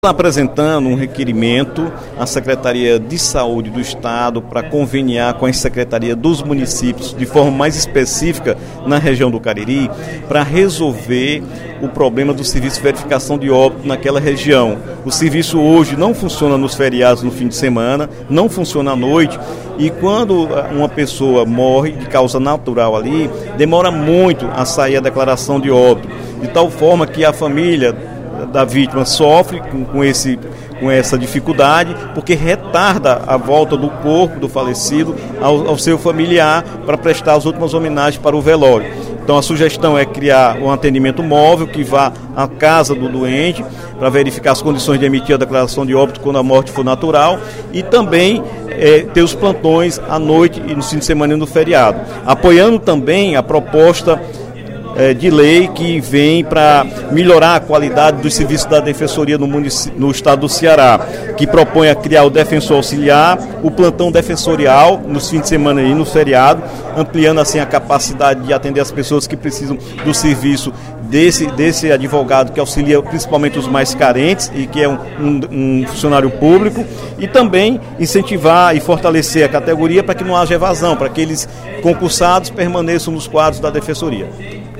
O deputado Dr. Santana (PT) destacou, no primeiro expediente da sessão plenária desta quarta-feira (15/06), requerimento, de sua autoria, que solicita a criação do serviço de verificação de óbito móvel.